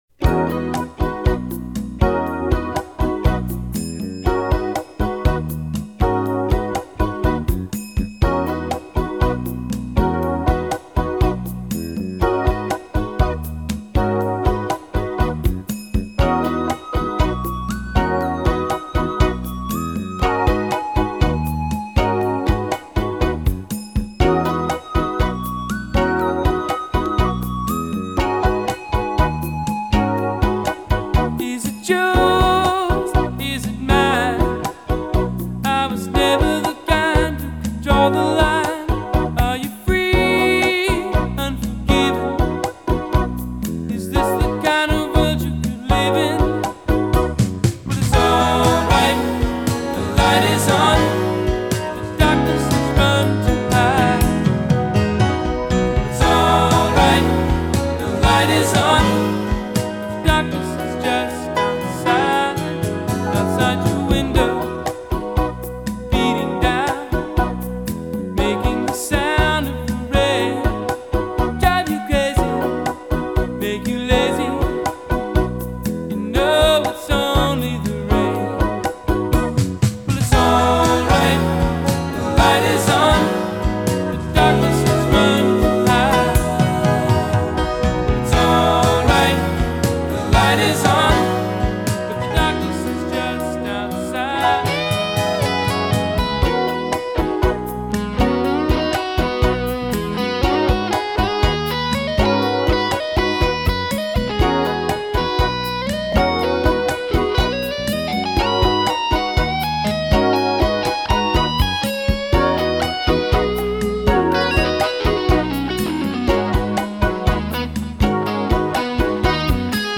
una hermosa canción